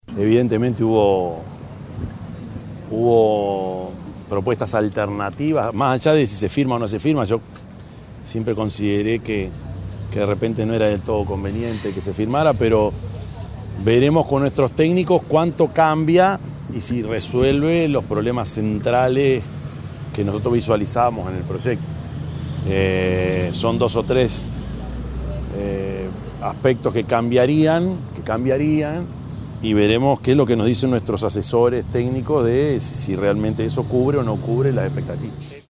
En rueda de prensa, el presidente electo se refirió al proyecto Arazatí y dijo que analizan las alternativas presentadas por el Ejecutivo.